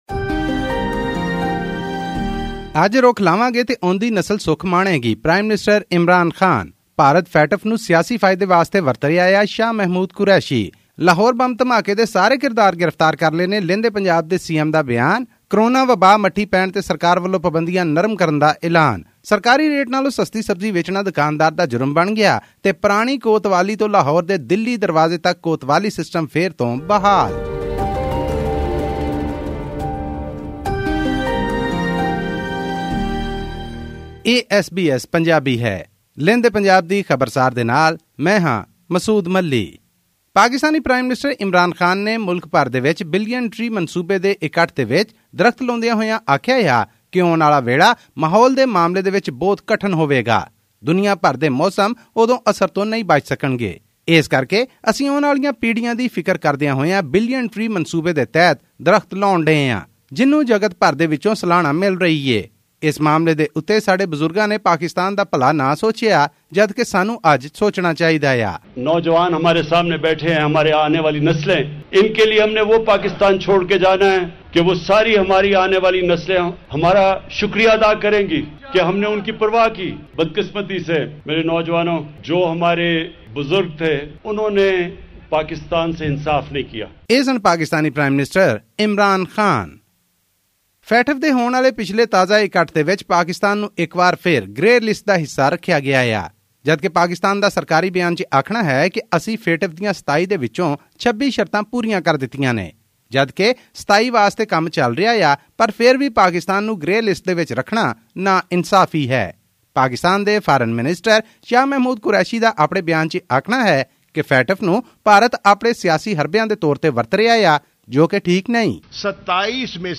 Pakistan's counterterrorism police has claimed to arrest ten suspects in connection with a bomb blast that took place in Johar Town, Lahore, on 23 June 2021. The car bomb explosion killed three and injured 22 people, including two policemen. This and more in our weekly news bulletin from Pakistan.